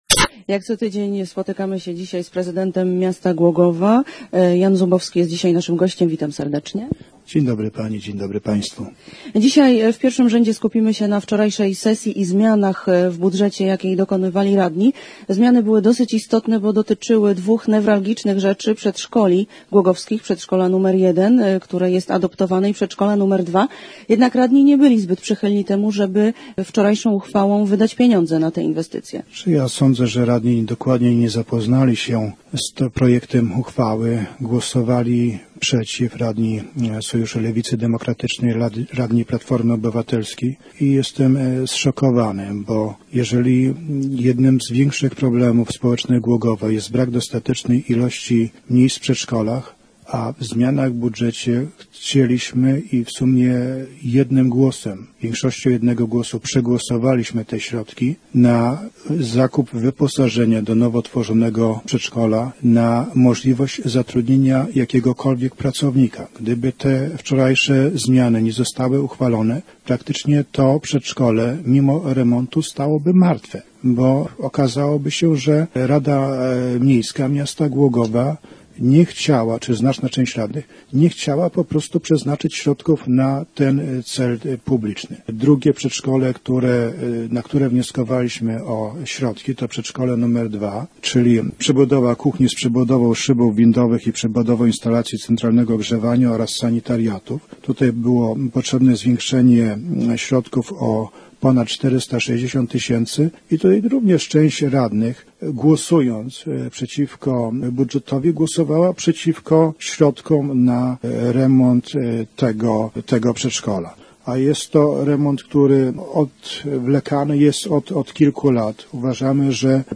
Radni zwiększyli środki na remont w Przedszkolu Publicznym nr 2 i przyznali dodatkowe fundusze na zatrudnienie, adaptację i wyposażenie przedszkola przy ul. Andromedy. Gościem Radia Elka był dzisiaj prezydent Jan Zubowski.